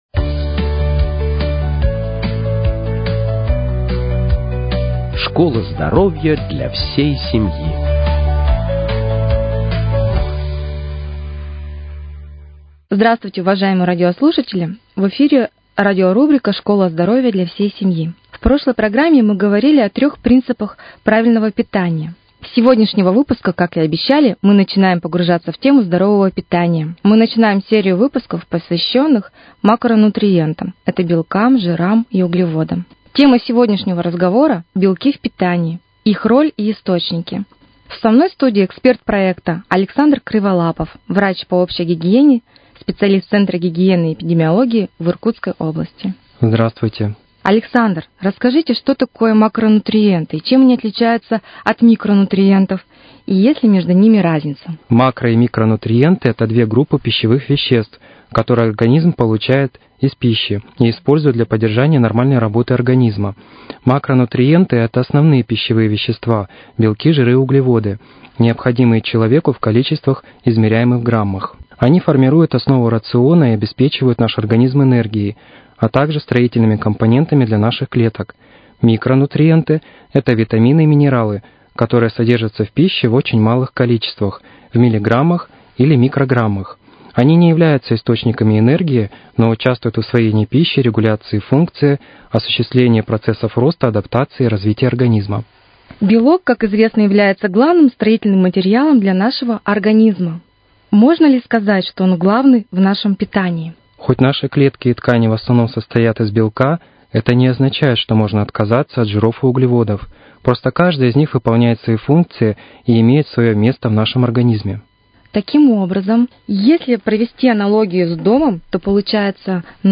Всё — простым и понятным языком, для всей семьи.